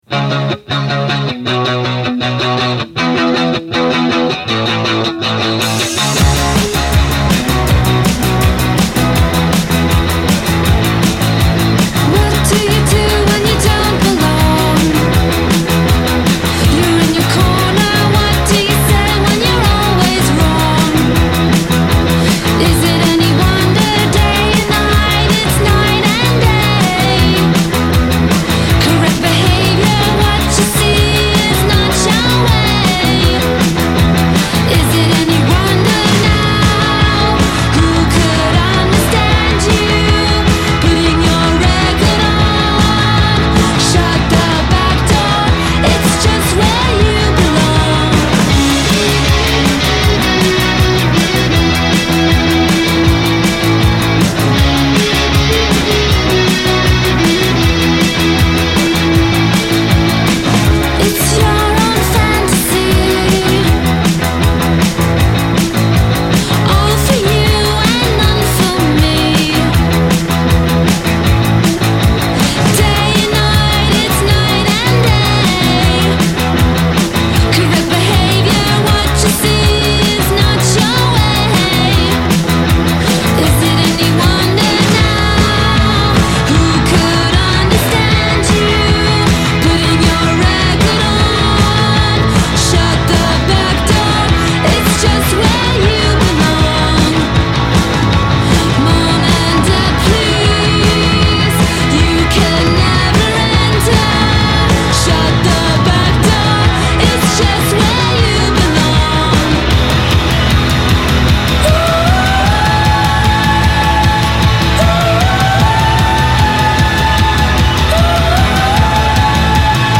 secco post punk
con riff poderosi, a volte solenni